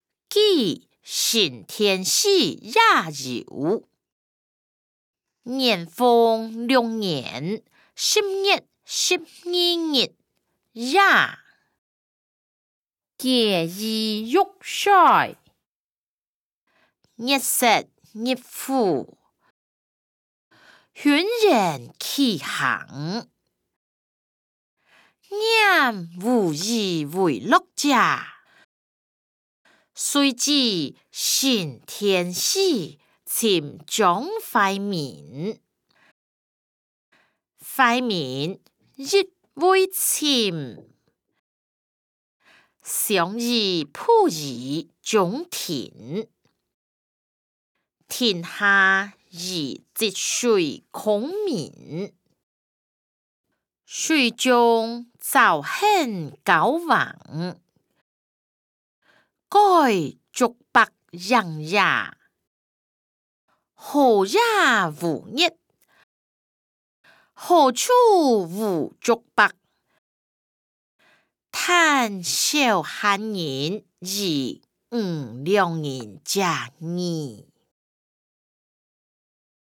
歷代散文-記承天寺夜遊音檔(大埔腔)